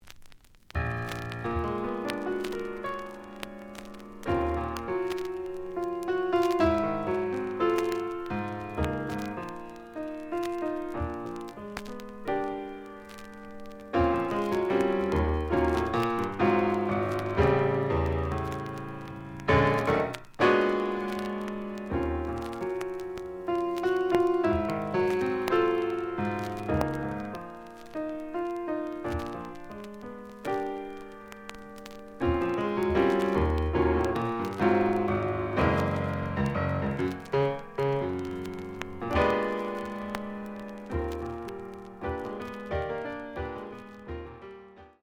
The audio sample is recorded from the actual item.
●Genre: Jazz Funk / Soul Jazz
Some noise on both side labels.